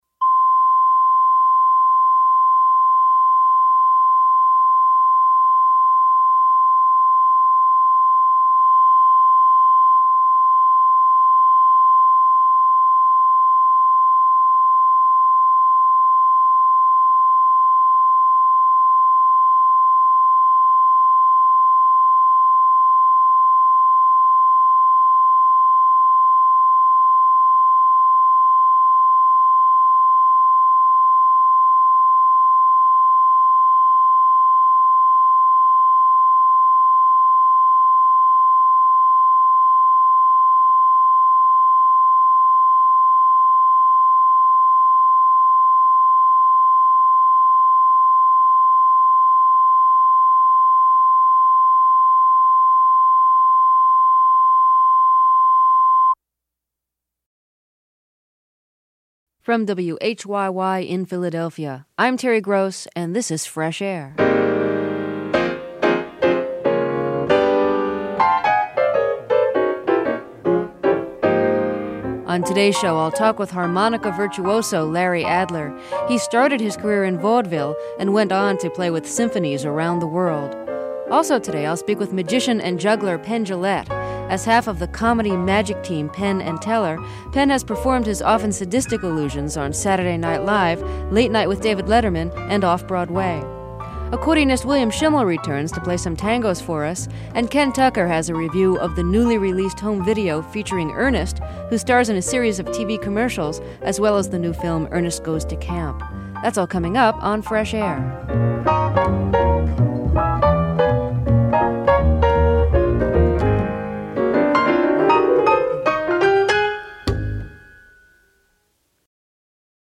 Interview Penn Jillette